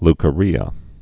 (lkə-rēə)